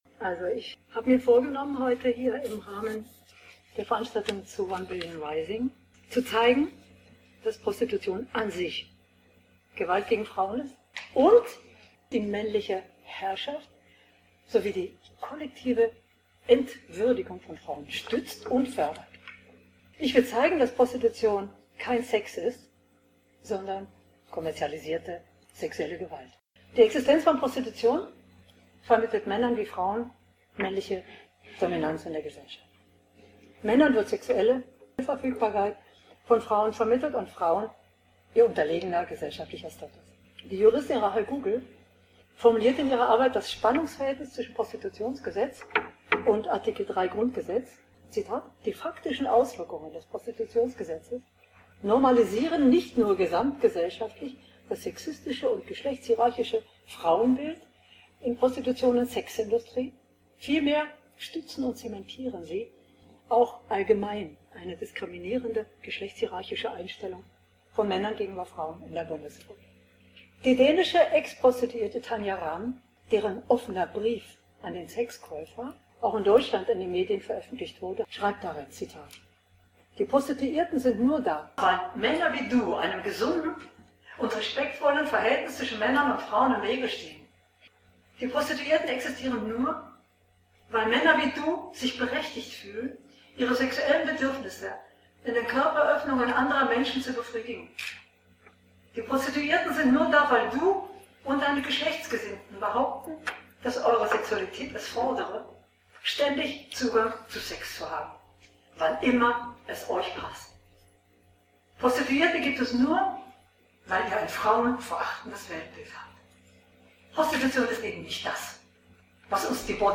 Sie zeigt, dass Prostitution kein Sex ist, sondern kommerzialisierte sexuelle Gewalt. In konsequenter Logik fordert sie das Verbot des Sexkaufs nach dem „schwedischen Modell“. – Original-Mitschnitt vom 07.02.2014, 18-19 Uhr, Ratssitzungssaal des Rathauses Osnabrück